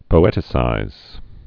(pō-ĕtĭ-sīz)